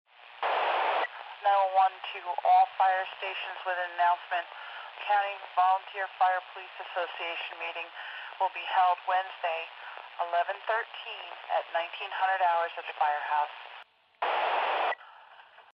Звуки рации